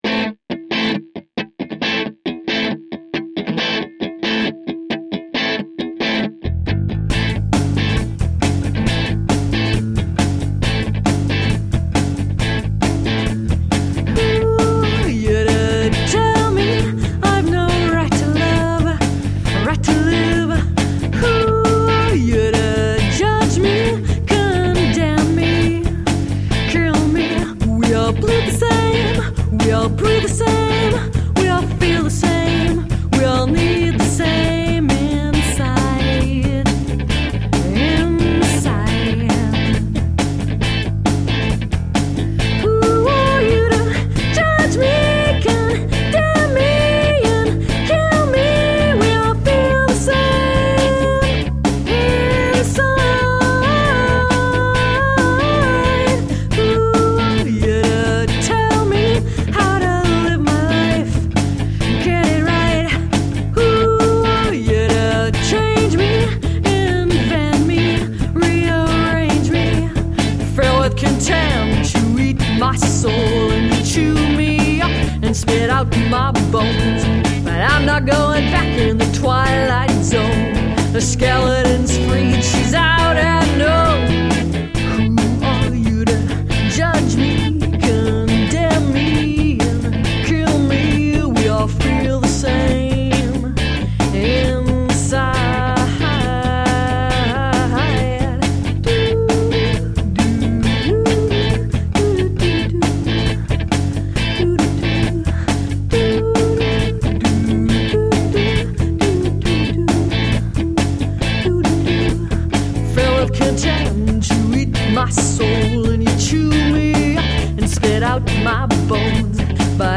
Jazzed up, funk-tinged eclectic pop..
Tagged as: Alt Rock, Rock, Ironic Rock